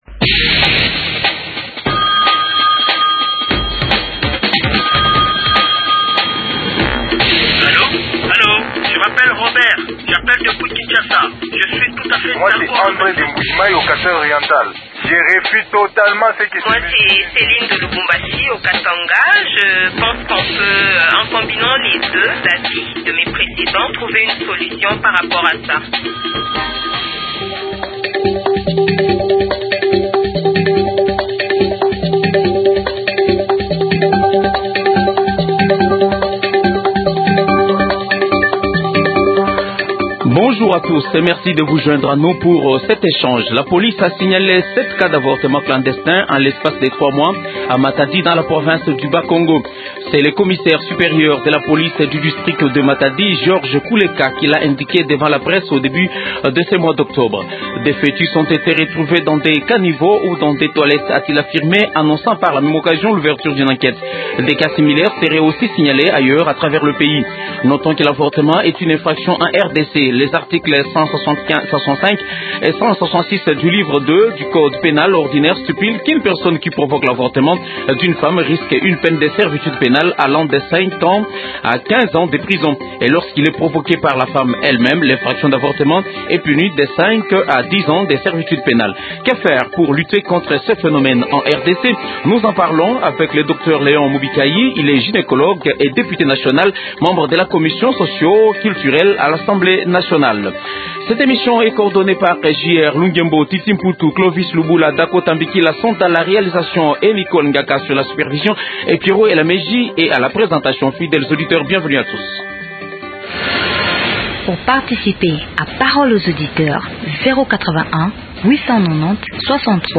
- Que faire pour lutter contre les avortements clandestins ? Invité: Docteur Léon Mubikayi, est gynécologue, et député national membre de la commission socio culture à l’assemblée nationale.